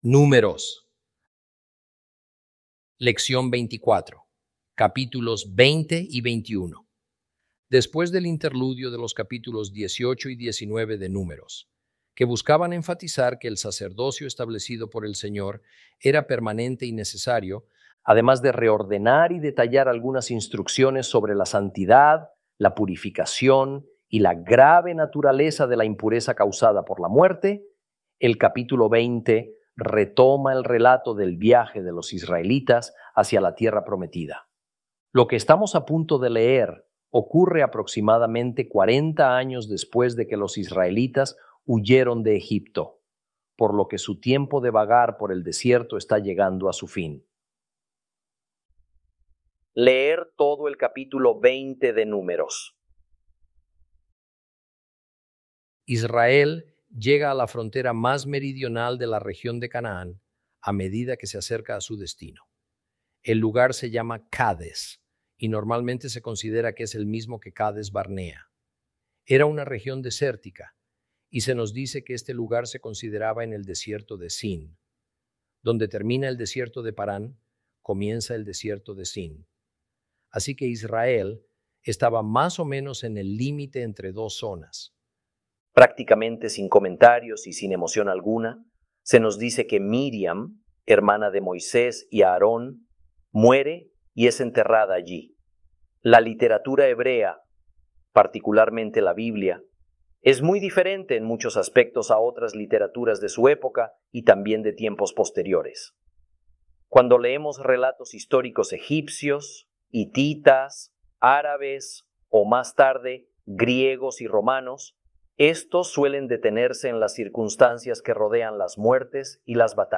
Lección 24 - Números 20 & 21 - Torah Class